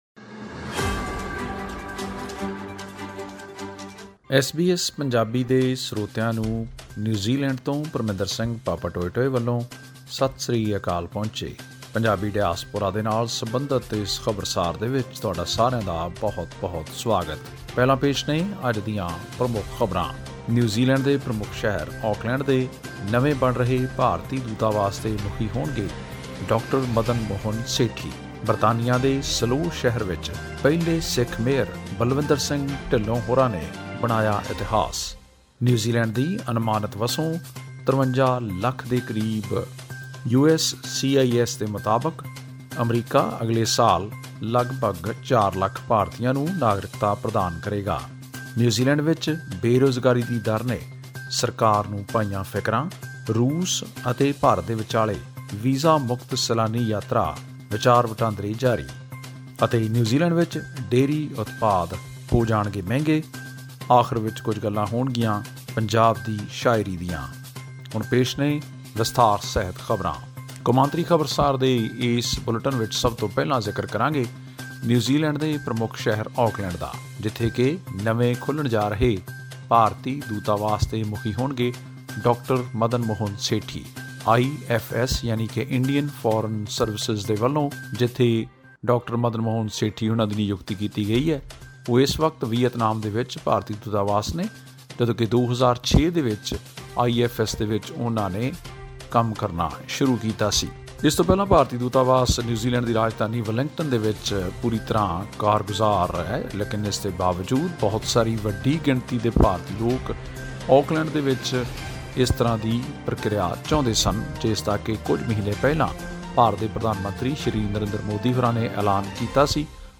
ਇਹ ਖਾਸ ਰਿਪੋਰਟ ਸੁਣੋ...